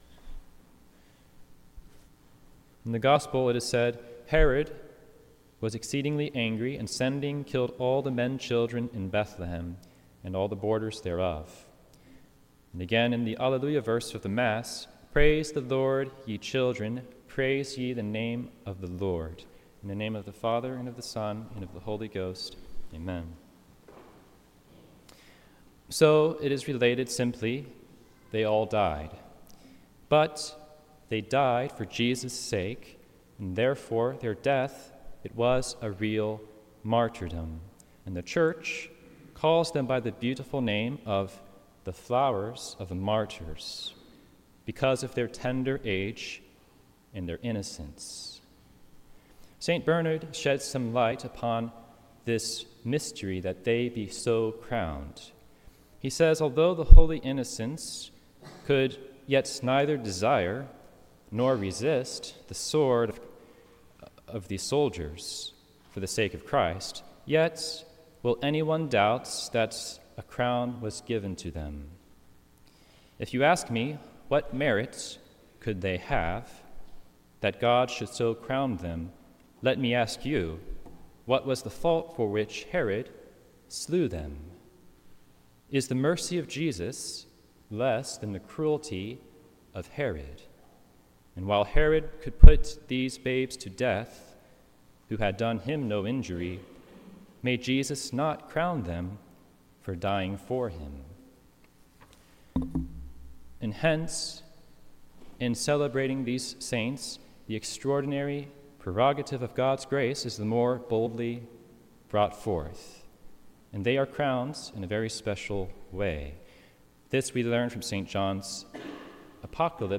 Sermons – St. Gertrude the Great